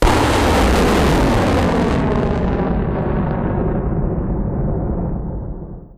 launch-gem.wav